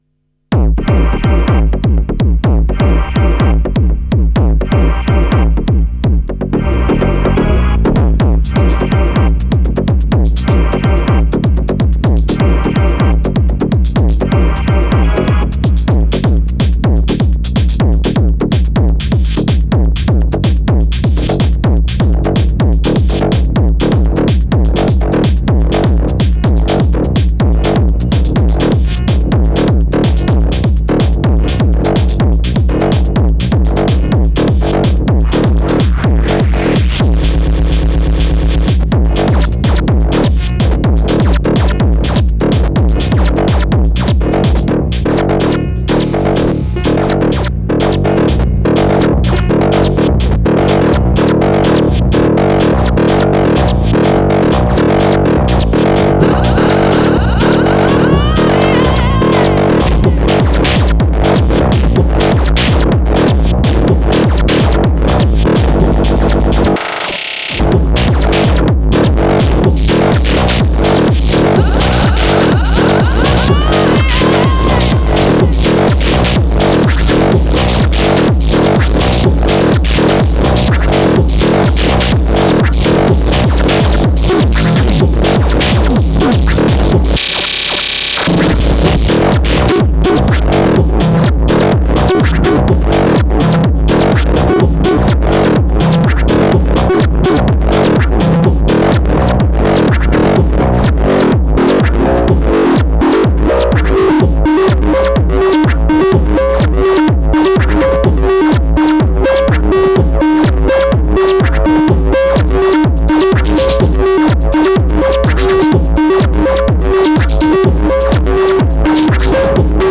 DFAM TR8s ‘TAKT and LXR02 … don’t get too close, you might loose an eye, or a face :boom::loud_sound: